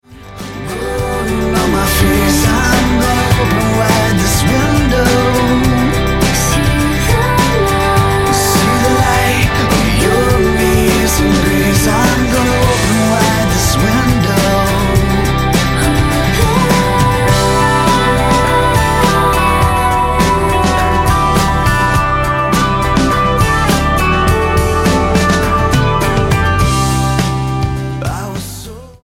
STYLE: Pop
Lots of jangling guitars